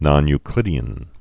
(nŏny-klĭdē-ən)